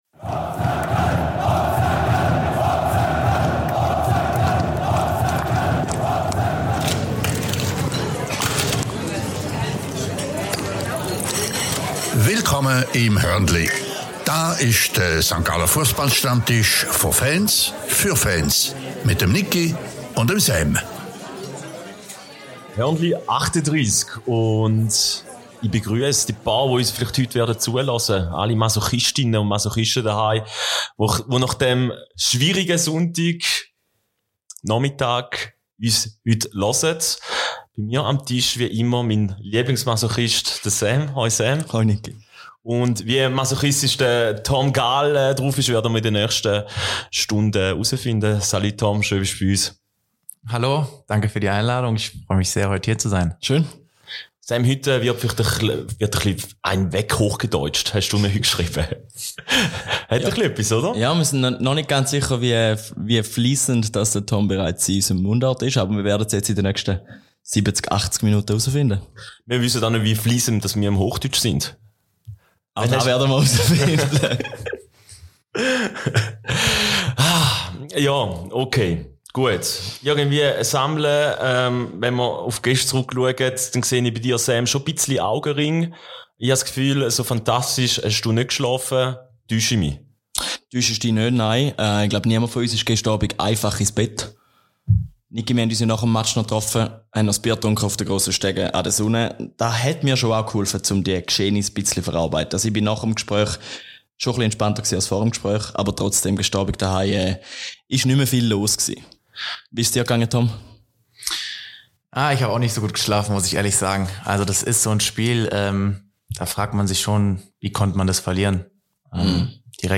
Die ärgerliche und vermeidbare Pleite gegen den FC Luzern wird im Trio aufgearbeitet.